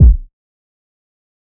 Portland Kick 2.wav